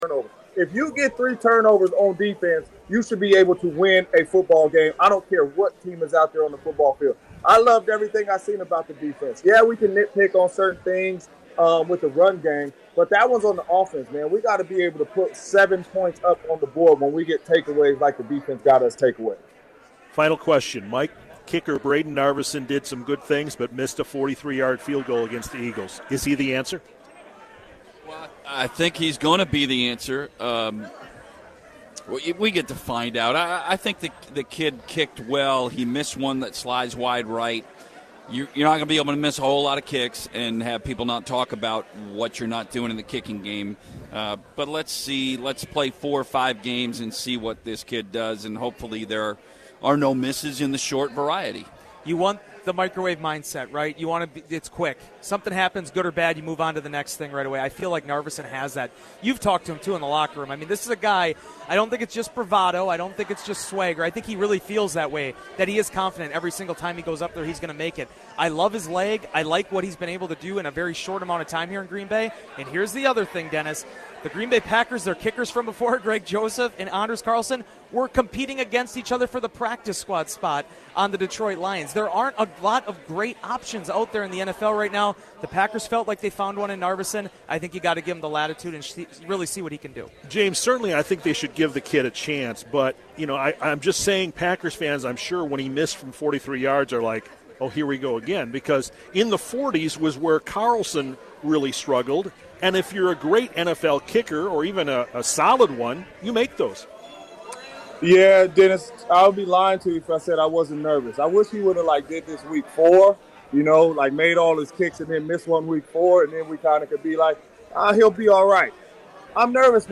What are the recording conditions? Broadcasts live 7 a.m. to noon Sunday mornings across Wisconsin.